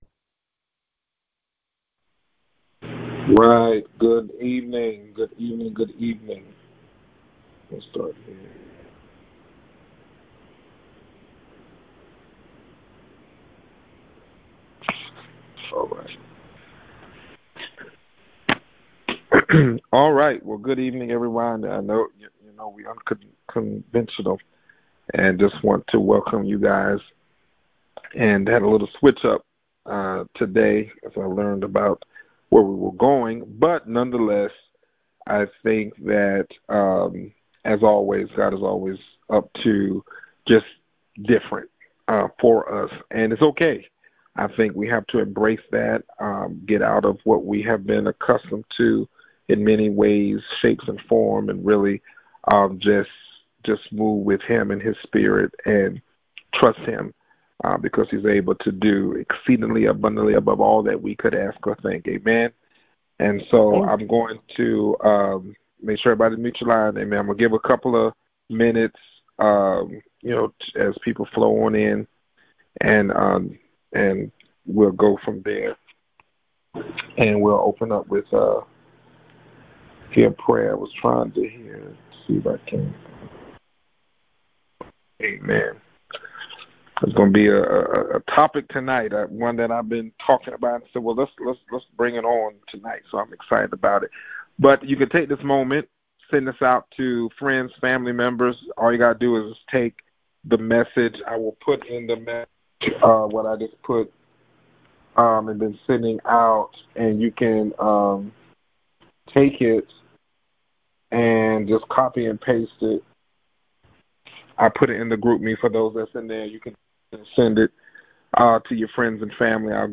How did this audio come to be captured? Access recorded conference from a phone or computer.